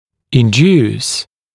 [ɪn’djuːs][ин’дйу:с]индуцировать; вызывать (какое-либо явление) ; побуждать, стимулировать